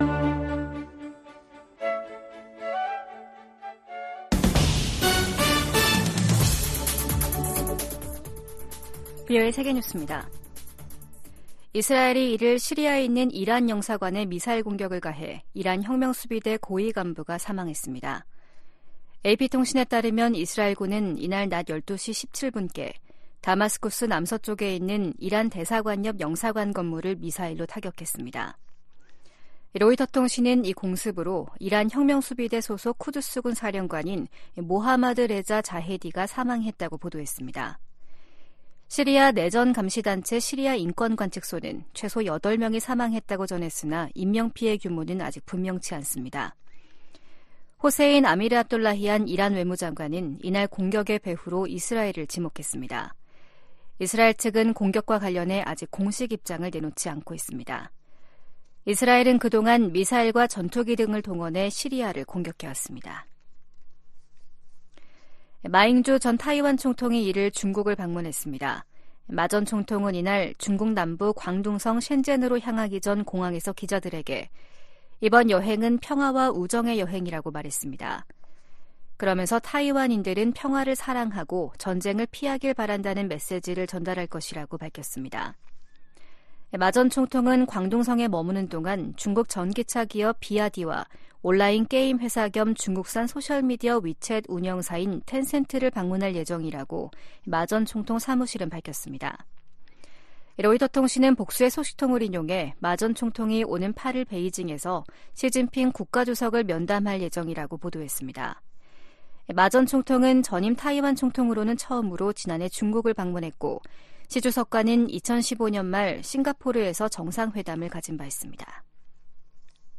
VOA 한국어 아침 뉴스 프로그램 '워싱턴 뉴스 광장'입니다. 미국은 유엔 안보리 대북제재위 전문가패널의 활동 종료가 서방의 책임이라는 러시아 주장을 일축하고, 이는 북한의 불법 무기 프로그램을 막기 위한 것이라고 강조했습니다. 미국과 한국, 일본이 북한의 사이버 위협에 대응하기 위한 실무그룹 회의를 열고 지속적인 협력 방침을 확인했습니다.